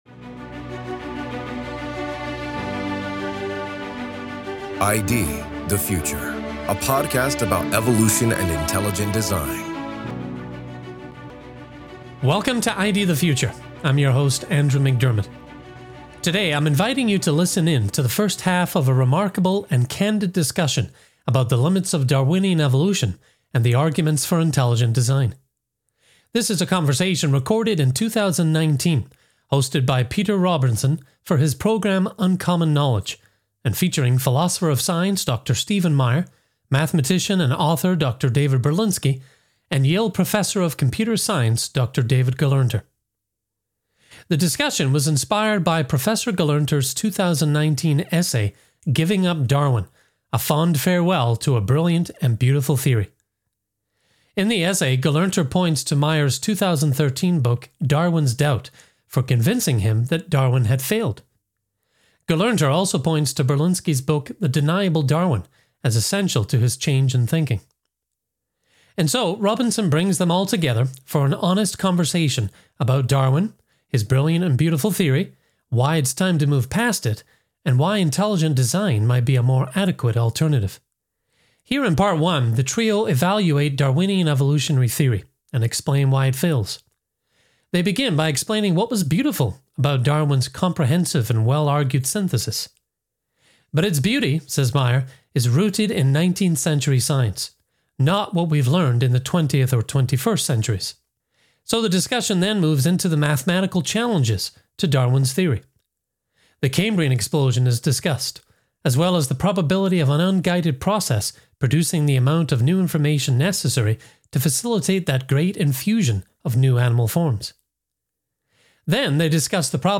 The conversation, originally recorded in 2019, is hosted by Peter Robinson for Uncommon Knowledge, and features philosopher of science Dr. Stephen Meyer, mathematician and author Dr. David Berlinski, and Yale Professor of Computer Science Dr. David Gelernter.